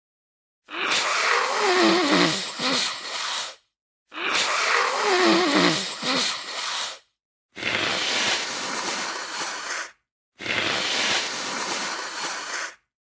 Tiếng Hỉ mũi, Xì mũi, chảy nước mũi
Thể loại: Tiếng con người
Description: Download miễn phí hiệu ứng âm thanh tiếng hỉ mũi (hay còn gọi là xì mũi, xịt mũi, tiếng sụt sịt, chảy nước mũi...) với âm thanh chân thực, rõ nét, giúp tăng tính hài hước hoặc tạo điểm nhấn độc đáo cho các cảnh phim, clip vui nhộn, video parody, vlog, hoạt hình, hay nội dung sáng tạo... sử dụng trong phần mềm chỉnh sửa video, dựng phim, làm hiệu ứng âm thanh cho TikTok, YouTube, Facebook…
tieng-hi-mui-xi-mui-chay-nuoc-mui-www_tiengdong_com.mp3